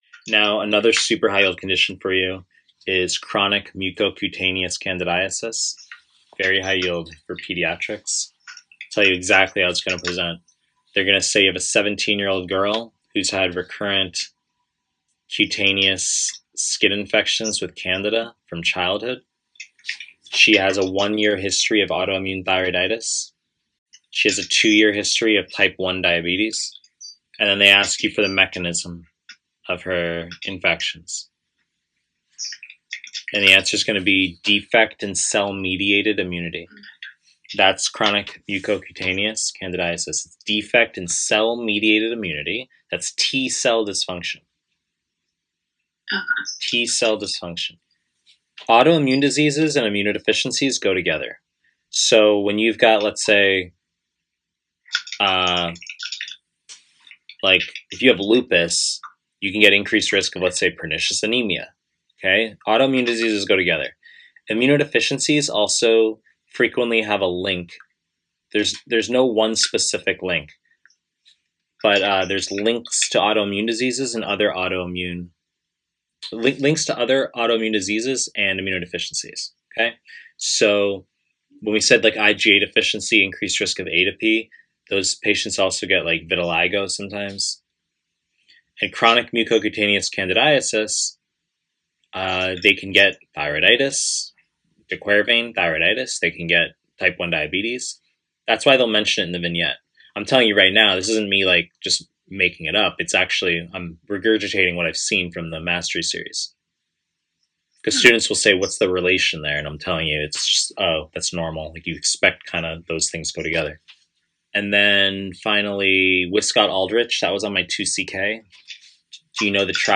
Pediatrics / Pre-recorded lectures